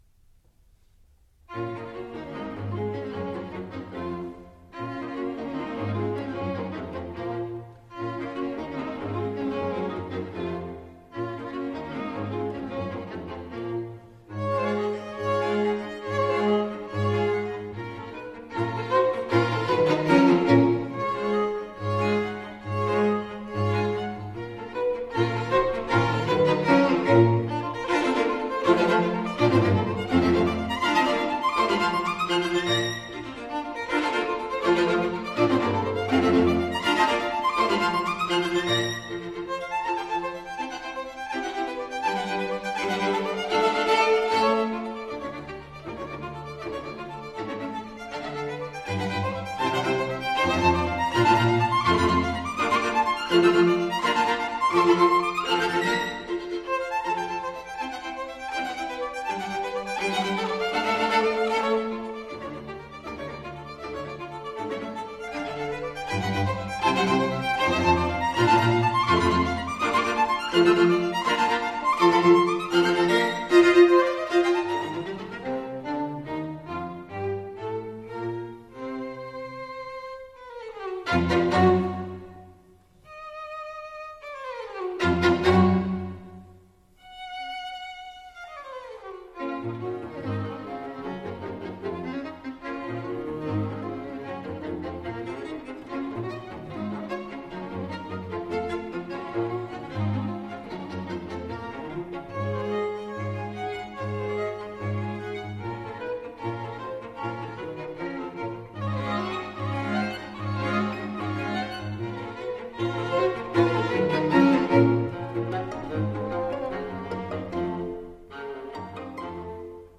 弦乐四重奏的组合看来简单，就两把小提琴，中提琴加大提琴，但历来为作曲家 们视为作曲技法的基本功。
Quartet In B Flat Major, Op. 130: 1.